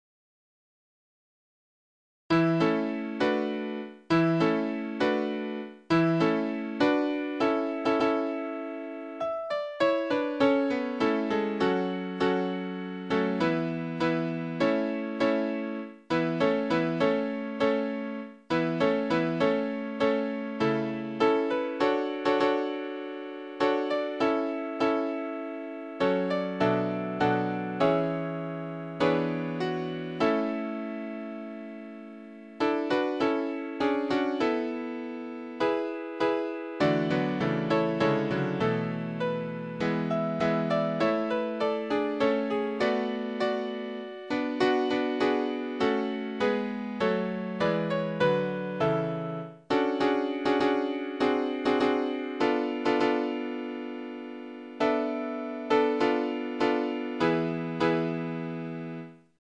pieśń z kategorii: psalmy